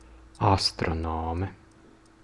Ääntäminen
Ääntäminen France: IPA: /as.tʁɔ.nɔm/ Haettu sana löytyi näillä lähdekielillä: ranska Käännös Ääninäyte 1. astronoms {m} 2. astronome {f} Suku: m .